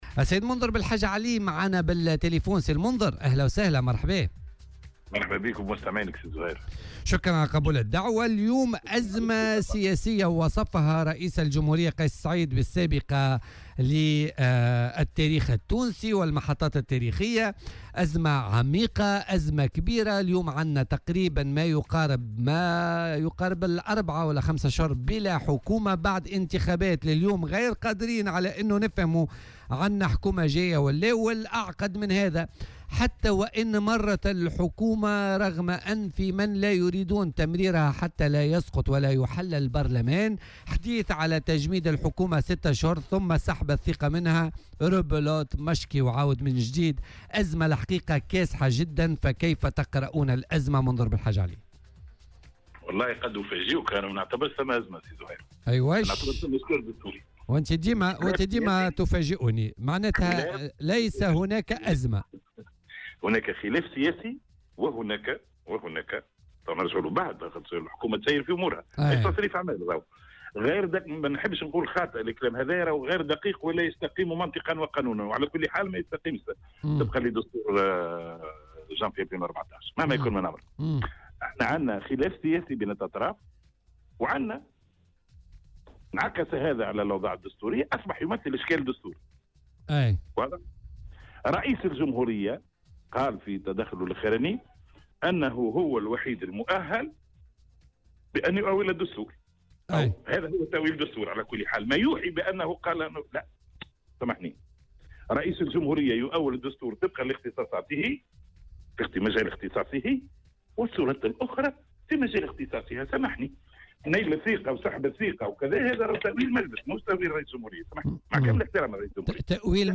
كما انتقد في مداخلة هاتفية مع "بوليتيكا" على "الجوهرة أف أم" القراءات التي ترى عدم القدرة دستوريا على سحب الثّقة من حكومة يوسف الشاهد.